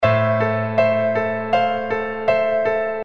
描述：解释：！创造你的钢琴！这里有所有的C调钢琴和弦。这里有所有C大调的钢琴和弦，用这些曲子你可以创造你自己的钢琴曲。构建你喜欢的钢琴旋律。玩得开心 )
Tag: 80 bpm Hip Hop Loops Piano Loops 516.84 KB wav Key : Unknown